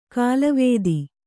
♪ kālavēdi